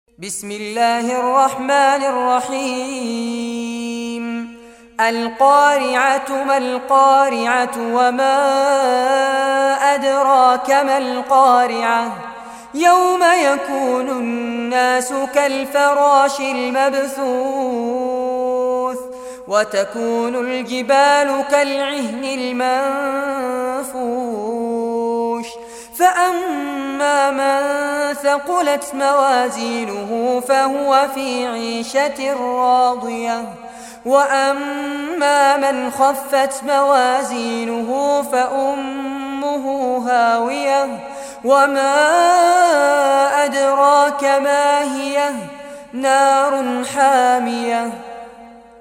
Surah Qariah Recitation by Fares Abbad
Surah Qariah, listen or play online mp3 tilawat / recitation in Arabic in the beautiful voice of Sheikh Fares Abbad.